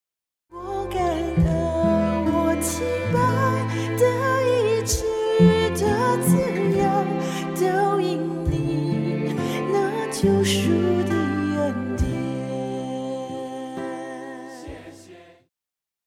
套鼓(架子鼓)
乐团
教会音乐
演奏曲
独奏与伴奏
有主奏
有节拍器